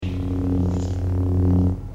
hum_2.wav